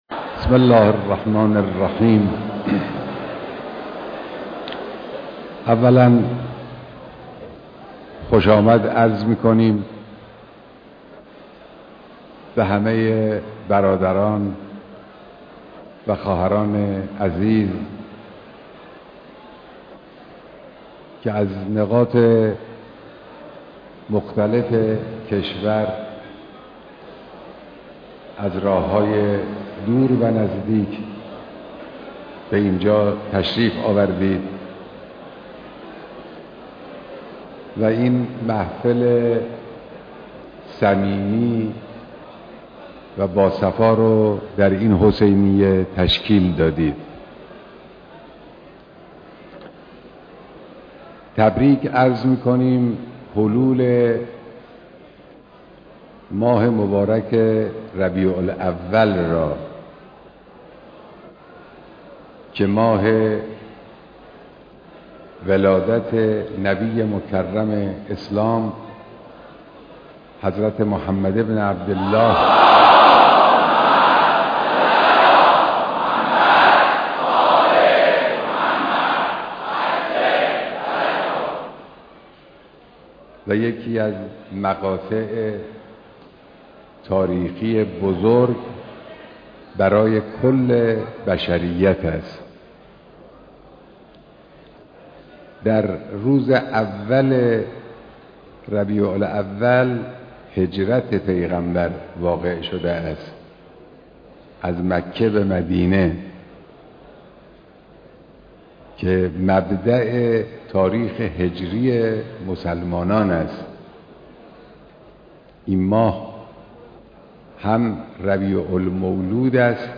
دیدار جمعی از اقشار مختلف مردم با رهبر معظم انقلاب